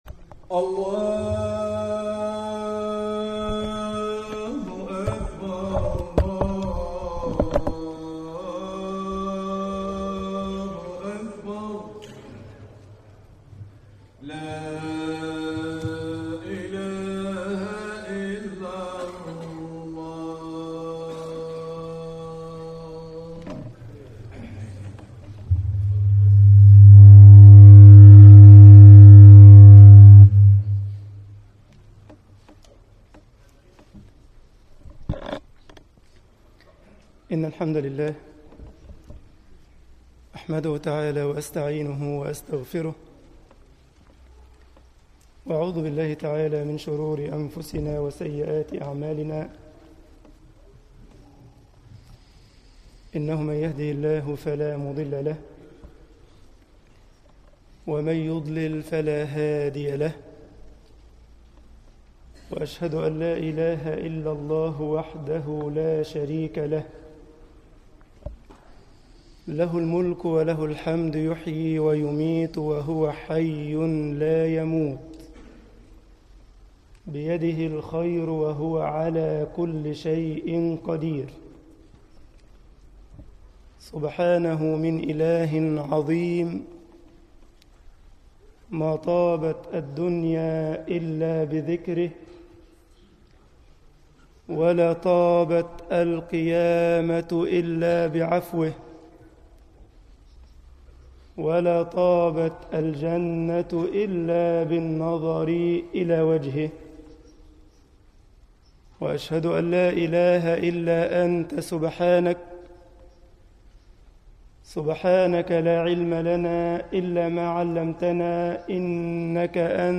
مسجد الجمعية الاسلامية بالسارلند خطبة الجمعة